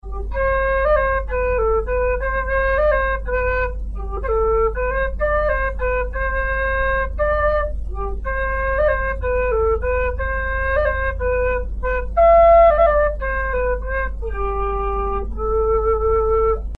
Вистл (G, пластик)
Вистл (G, пластик) Тональность: G
Составной вистл из пластика.